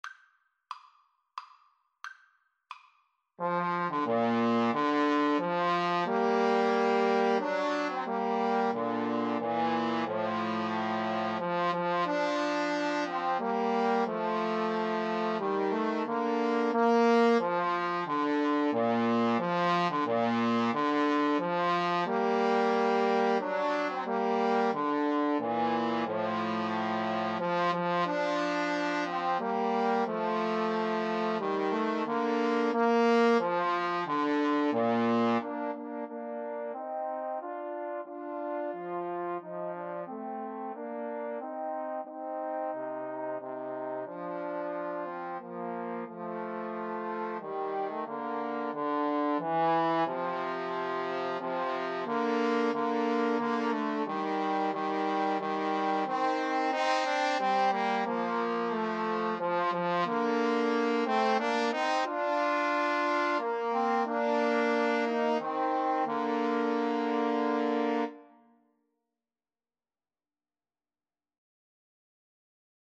3/4 (View more 3/4 Music)
Bb major (Sounding Pitch) (View more Bb major Music for Trombone Trio )
Maestoso = c.90
Trombone Trio  (View more Intermediate Trombone Trio Music)